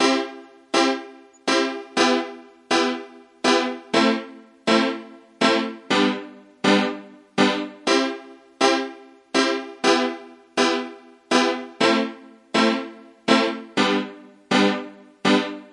描述：钢琴圈和弦122bpm
Tag: 和弦 循环 钢琴